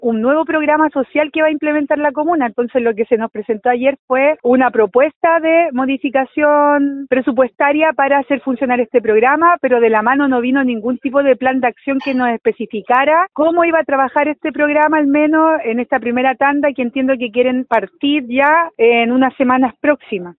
La concejal Stephany Hurtado sostuvo que preguntaron si había un plan de trabajo o de funcionamiento del lugar.